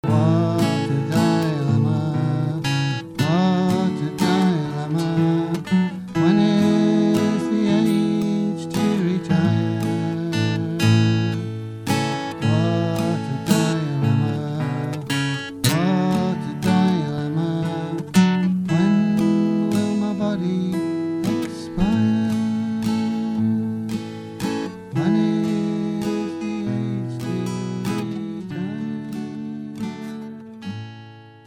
Influences 60s and 70s rock folk, country and pop.